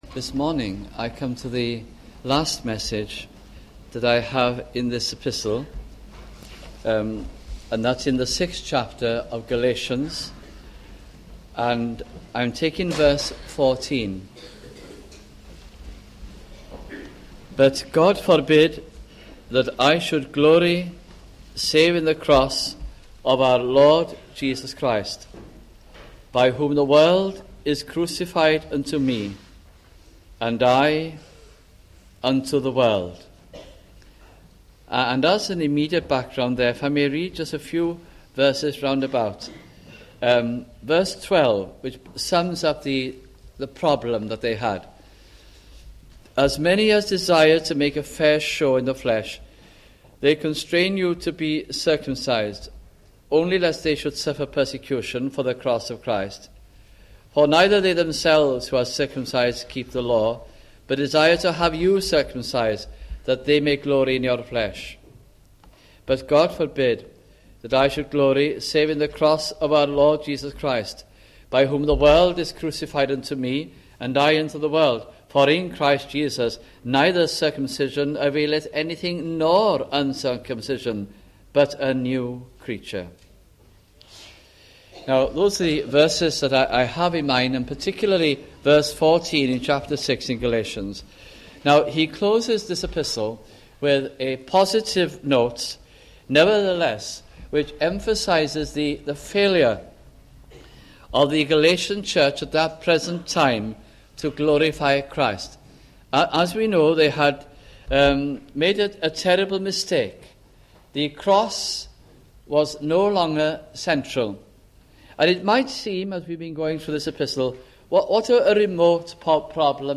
» Galatians Series 1986 » sunday morning messages